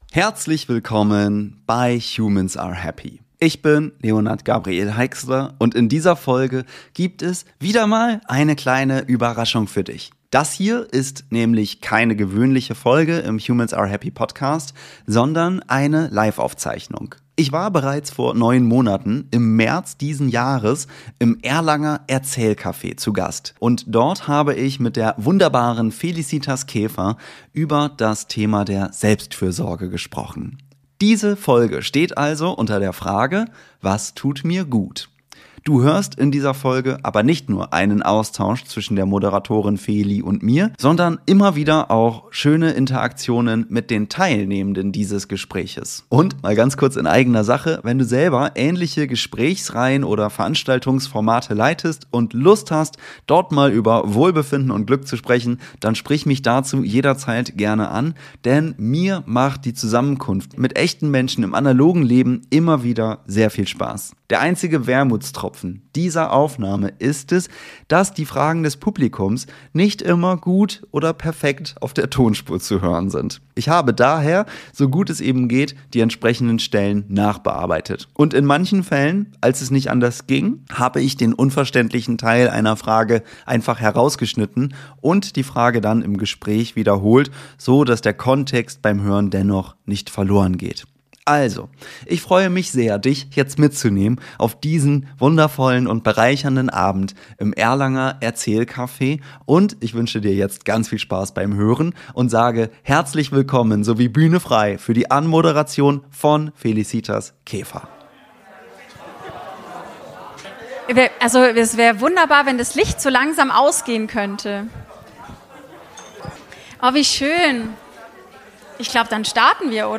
– Warum fällt Selbstfürsorge so vielen schwer – trotz aller Tipps da draußen? Ein besonderes Highlight der Folge: Die Hörer*innen der Live-Aufnahme waren aktiv dabei, haben Fragen gestellt, Impulse eingebracht und so das Gespräch mitgestaltet.